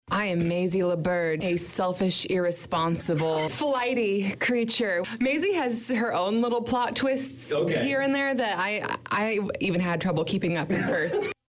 in the D102 studios